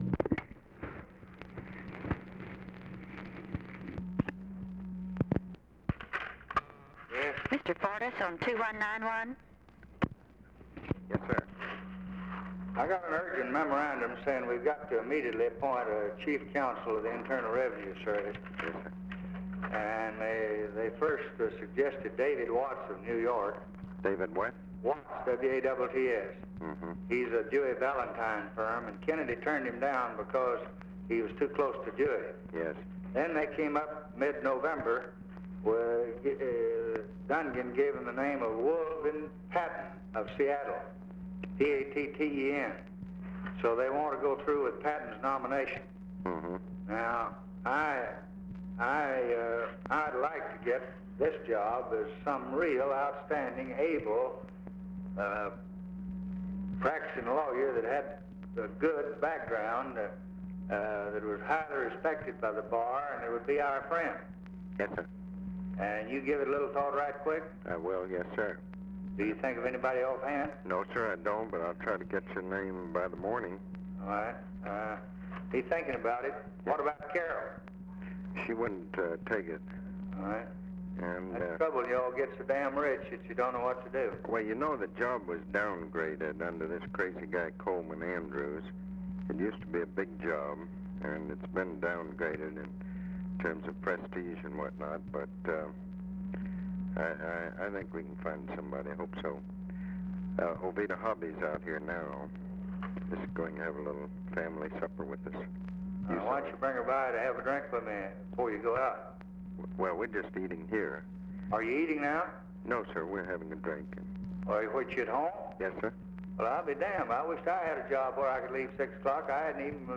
Conversation with ABE FORTAS, December 9, 1963
Secret White House Tapes